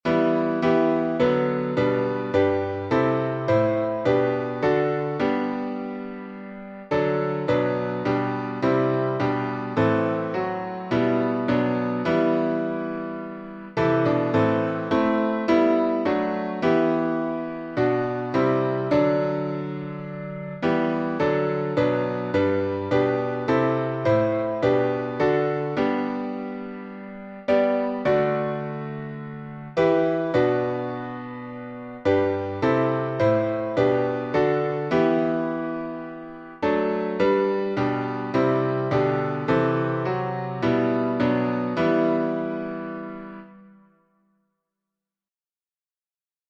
French processional melody